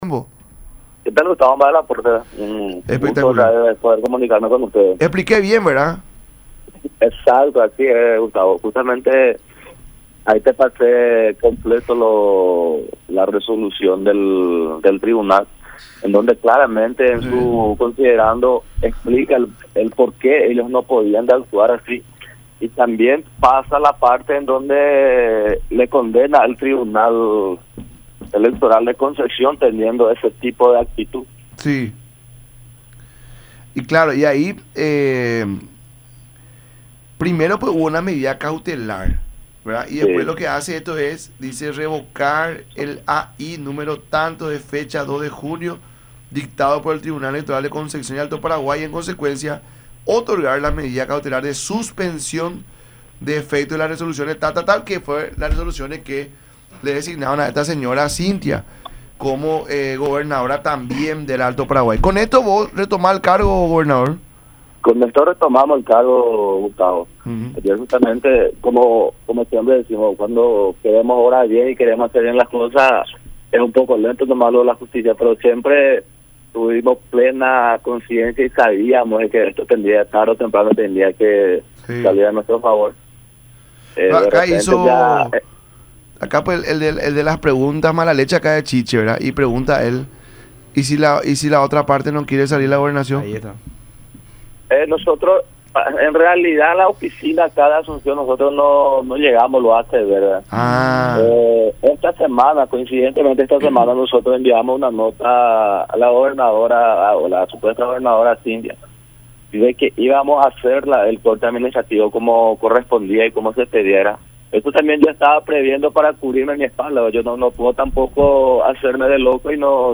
“Con esto retomamos el cargo de la gobernación, gracias a están dándonos nuevamente el cargo”, declaró en la nota con el programa “La Mañana De Unión” por Unión TV y radio La Unión.